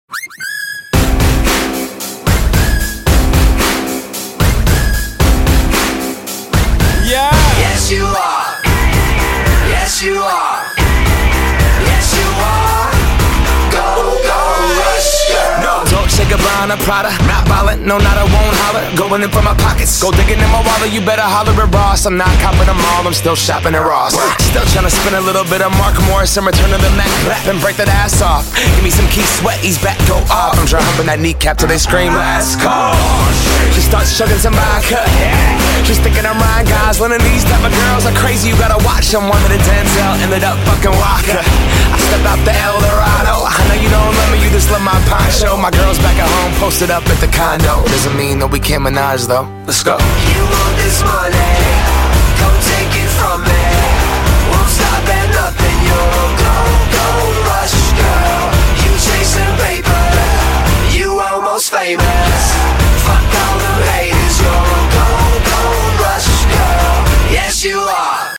• Качество: 192, Stereo
Крутой трек от целой кучи Рэперов